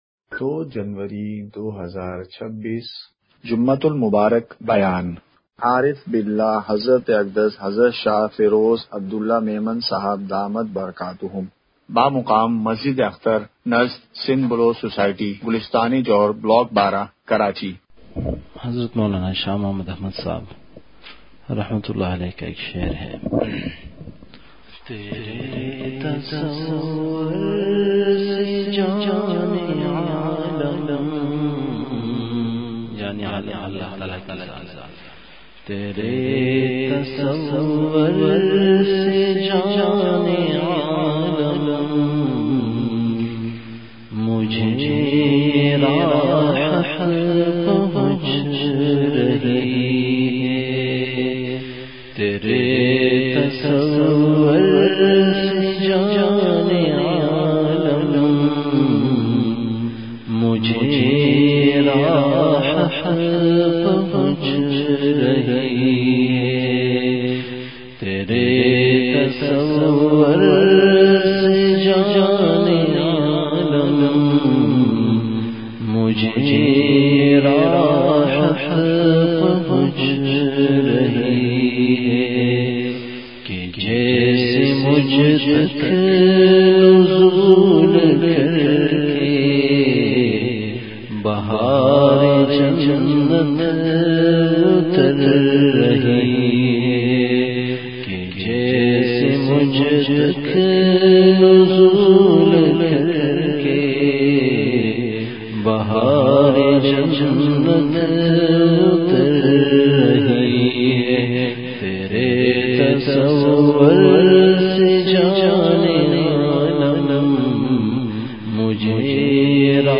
جمعہ بیان۲ جنوری ۲۶ ء:بے حیائی اور فحاشی کی روک تھام کا قرآنی نظام !
مقام:مسجد اختر نزد سندھ بلوچ سوسائٹی گلستانِ جوہر کراچی